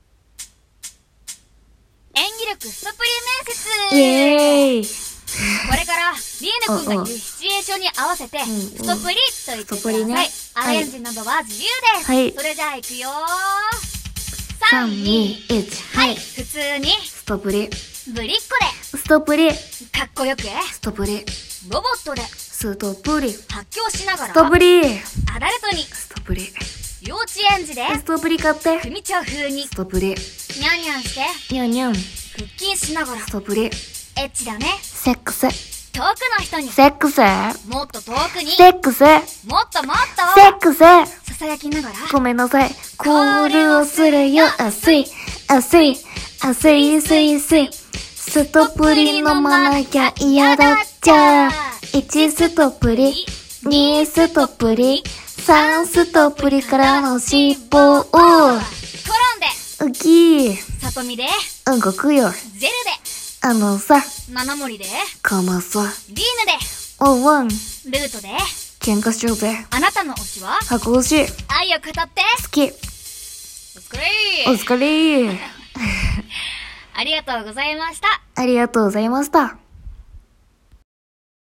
高音質